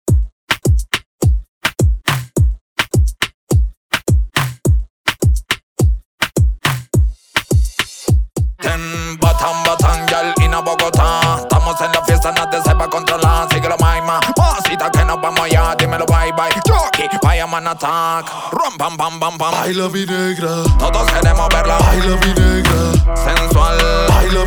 DJ and producer of tech house & house music
DJ